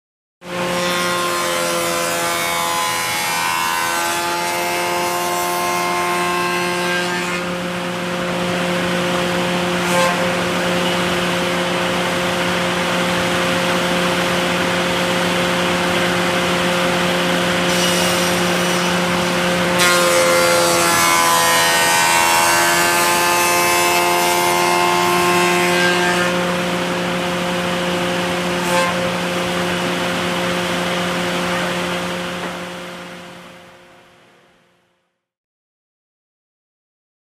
Mill Saw | Sneak On The Lot
Lumber Mill Saw; Cutting; Cutting Wood, Motor / Fan In Foreground, Medium Perspective.